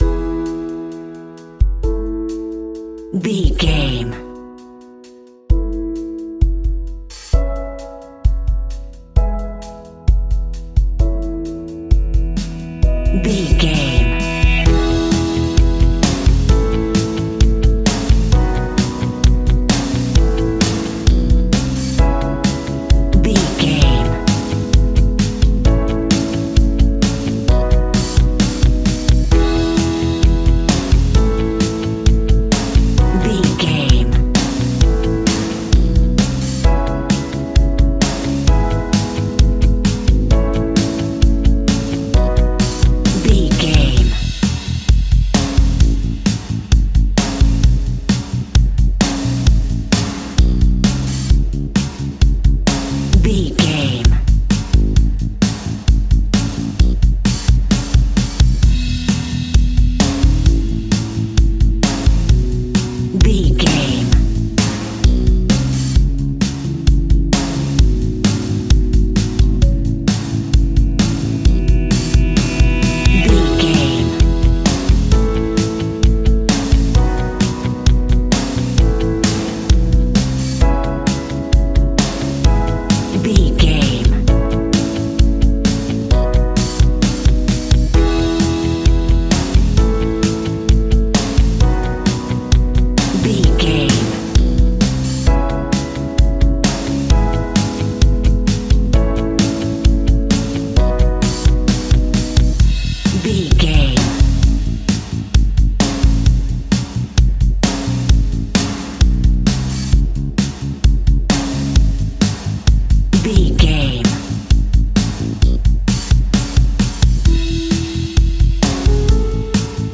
Uplifting
Ionian/Major
fun
energetic
indie pop rock music
indie pop rock instrumentals
guitars
bass
drums
piano
organ